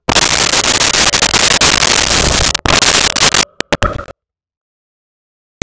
Creature Breath 03
Creature Breath 03.wav